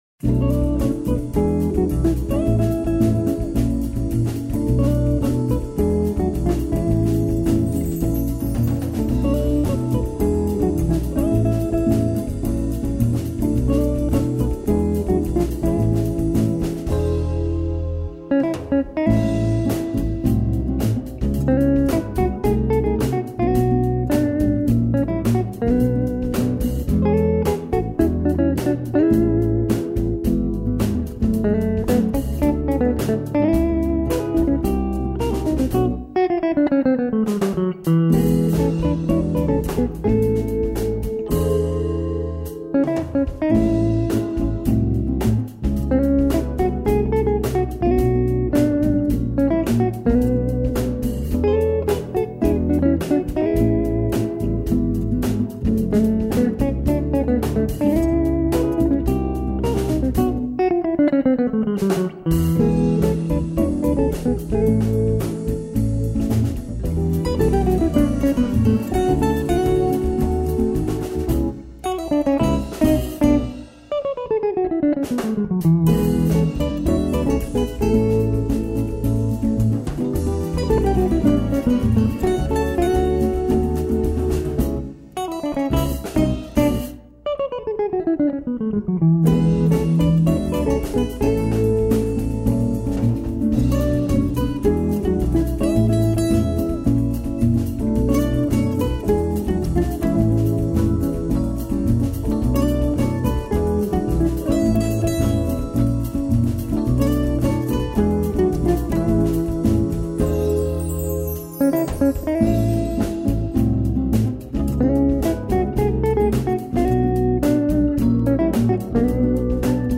2951   02:51:00   Faixa: 4    Samba